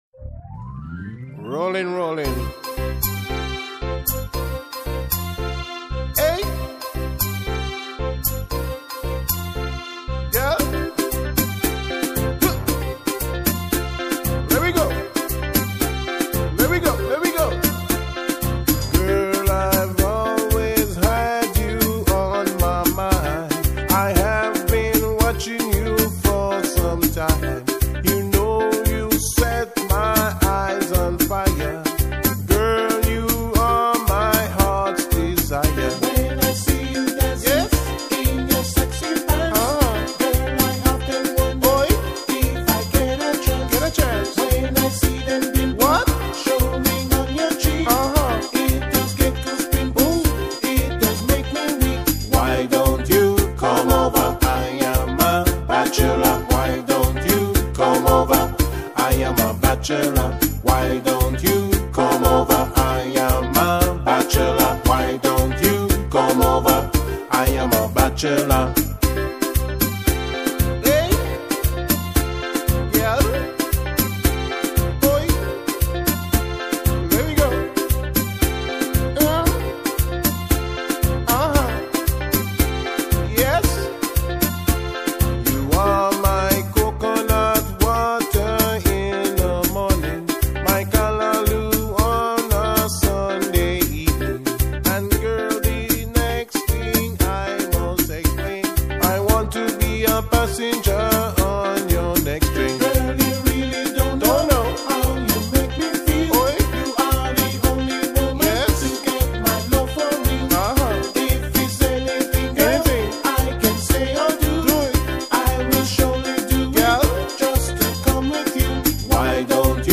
. max 12-piece band) 2) steel drum band with vocals 3) calypso, soca and/or reggae band (featuring the steel drums - with vocalist(s), guitar, bass, drums, percussion, horns, etc.)